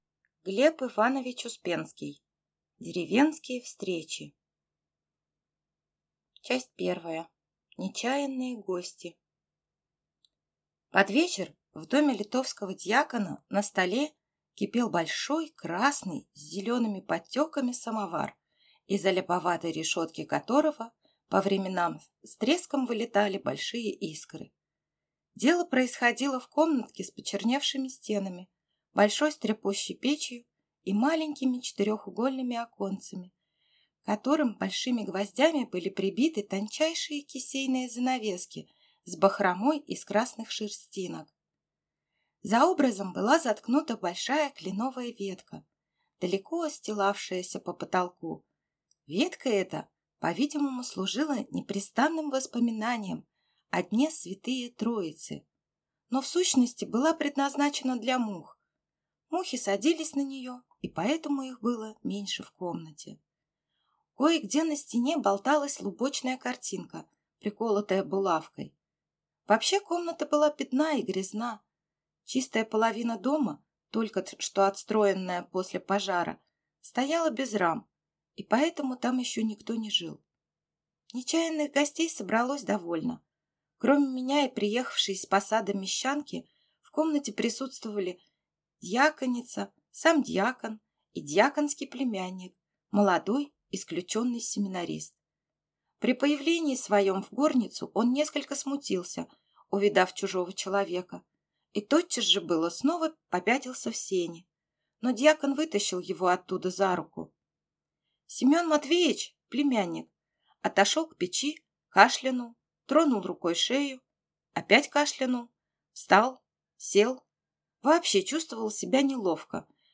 Аудиокнига Деревенские встречи | Библиотека аудиокниг